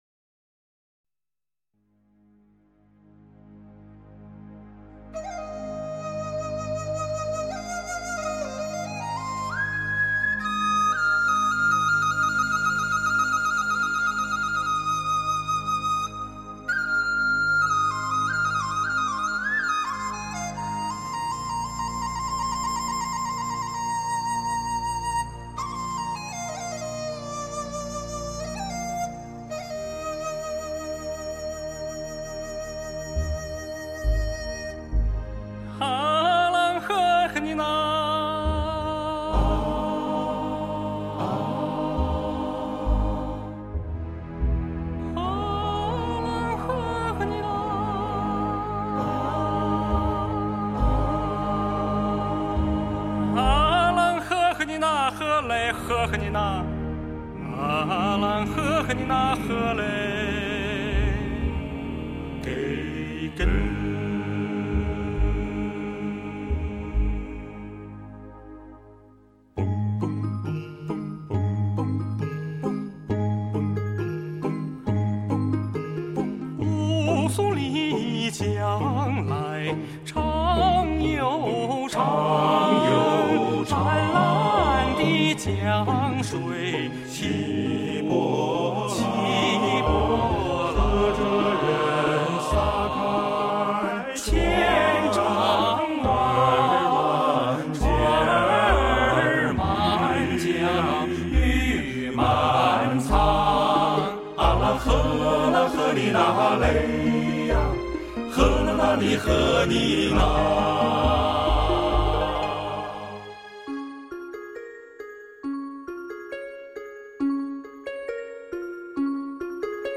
经典民歌魅力尽显，隽永精品深情演绎，承前启后，中西交融，亦庄亦谐，风格独具。